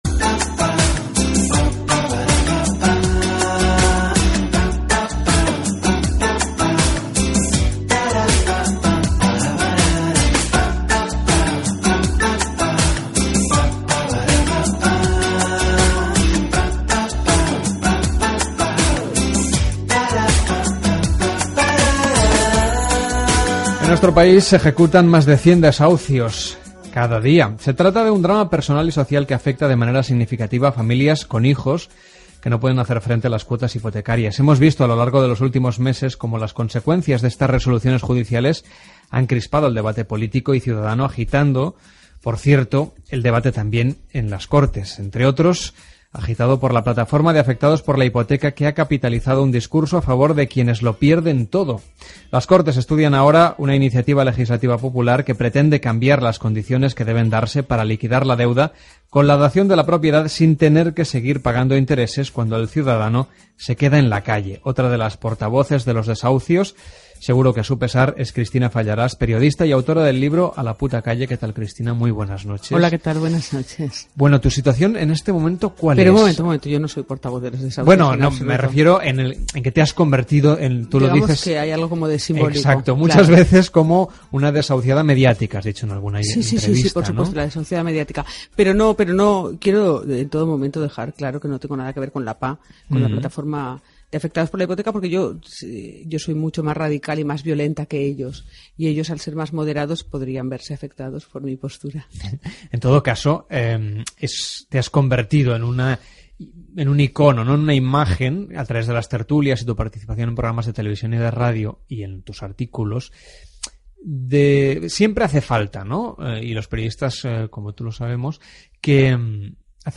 Entrevista a Cristina Fallarás, sobre els desaucis i la crisi de l'impagament d'hipoteques